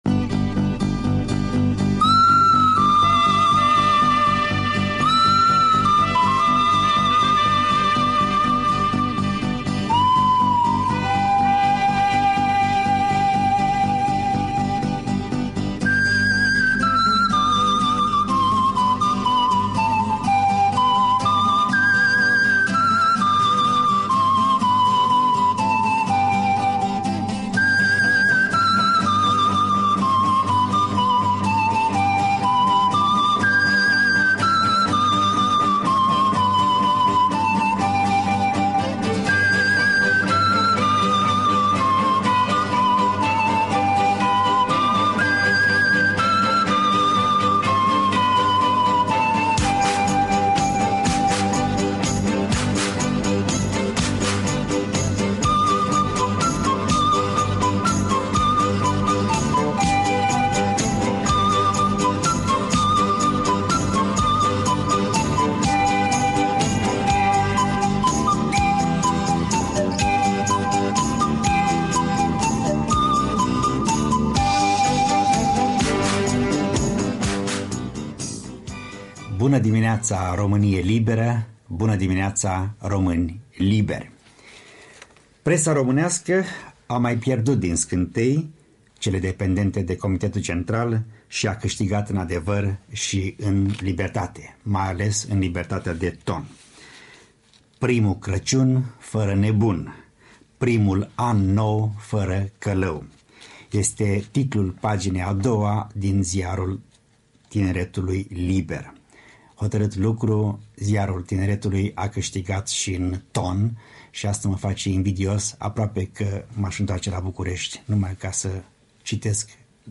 Un fragment din emisiunile Studioul special al „Actualității românești”, radio Europa Liberă, 27 decembrie 1989.